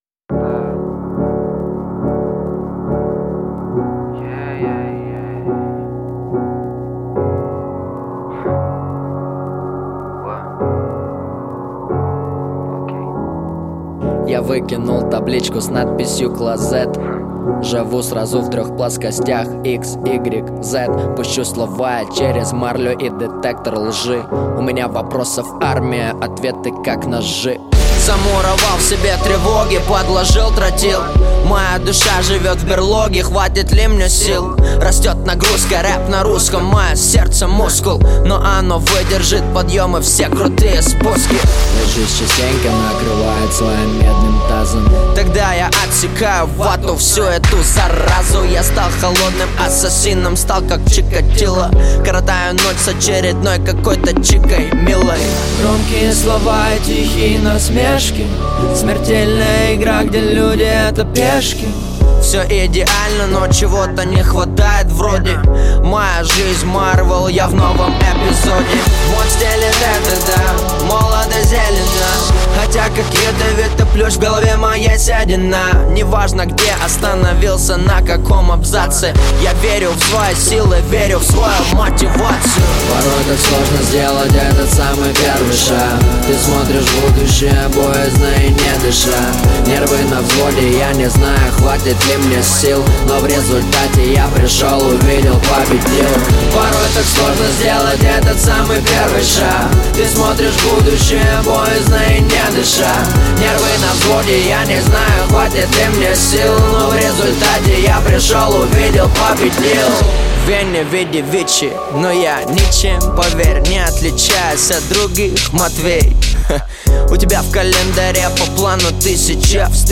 Жанр: Жанры / Хип-хоп